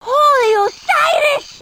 Háttérzenék
Extrém sportos zene